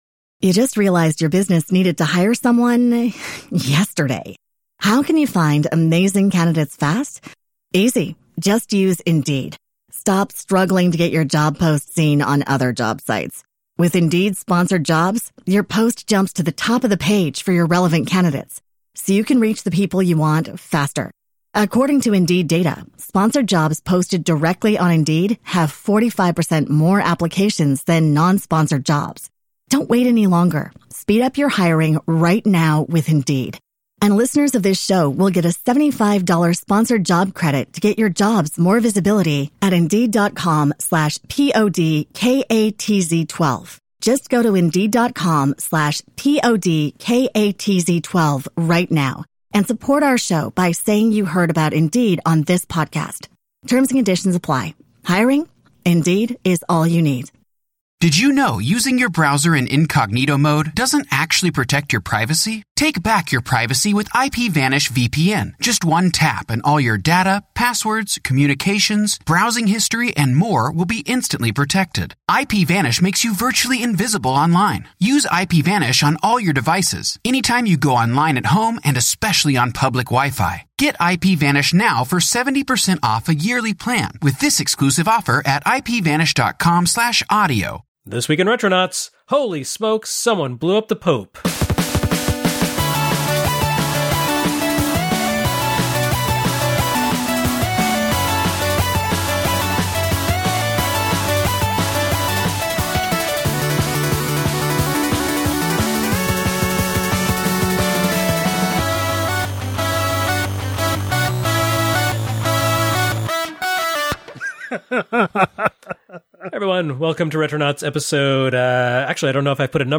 It's been quite a spiritual 2025 for Retronauts thus far: After episode 666 examined the Satanic Panic, we spent episode 672 discussing Christianity in video games, particularly the notion of toppling deities rather than honoring them. Naturally, that last topic proved too long for a single conversation, so it continues this week.